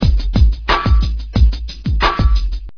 All these loops were created at loopasonic and are all original and copyright free.